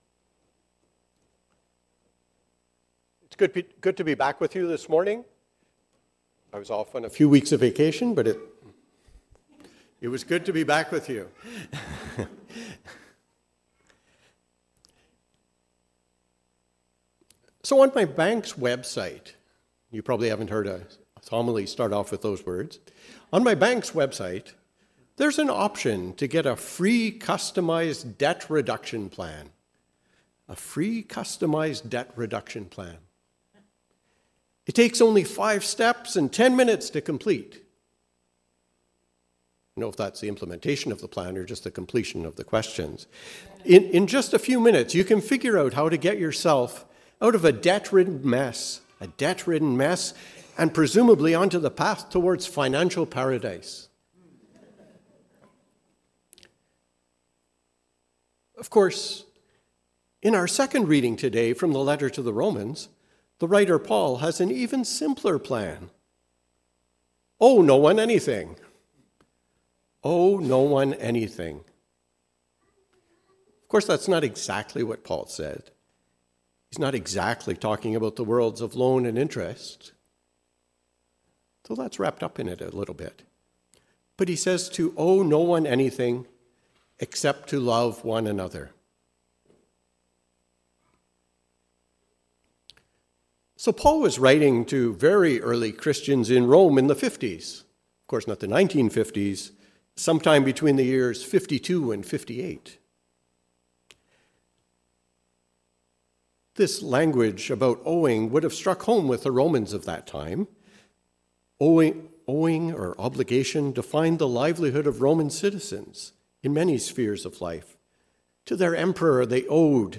A sermon on Romans 13:8-14 and Matthew 18:15-20.